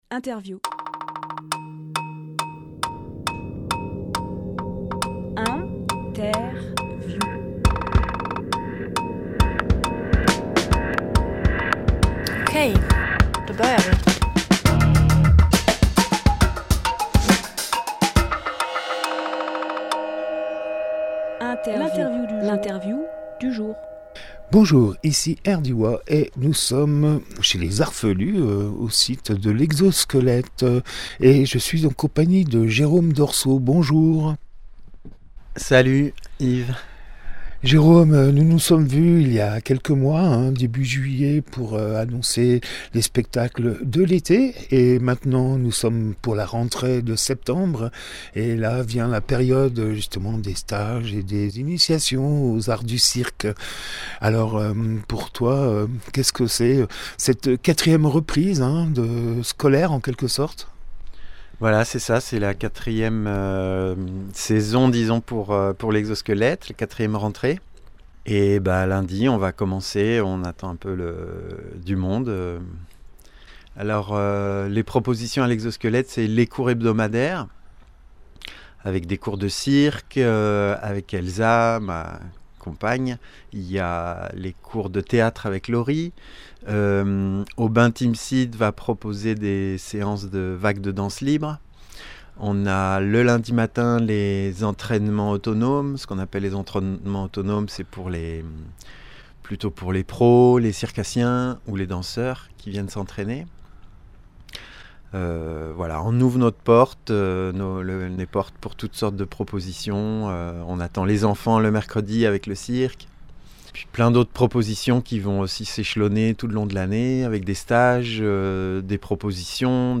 Emission - Interview Ateliers circassiens 2024-25 à l’Exosquelette Publié le 7 septembre 2024 Partager sur…
Lieu : Exosquelette, Luc-en-Diois